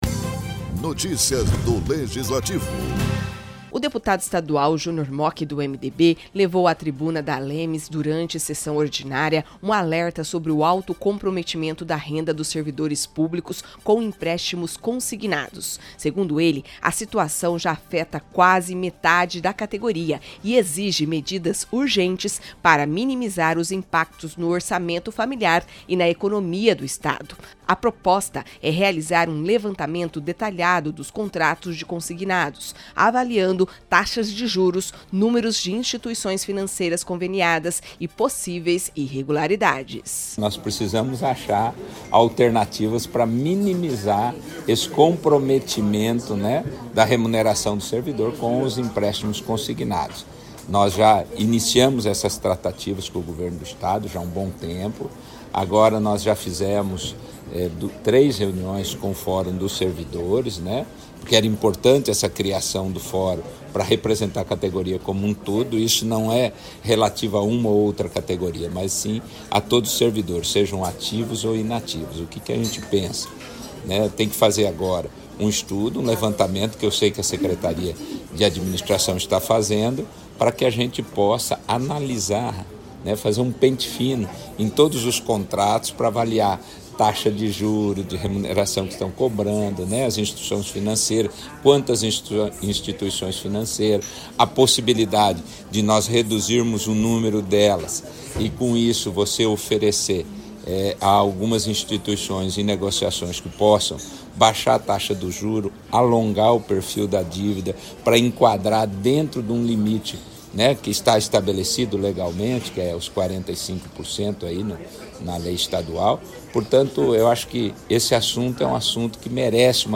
O deputado estadual Júnior Mochi (MDB) alertou, na tribuna da Assembleia Legislativa de Mato Grosso do Sul (ALEMS), para o elevado comprometimento da renda dos servidores públicos com empréstimos consignados e defendeu medidas urgentes para reduzir os impactos financeiros sobre a categoria.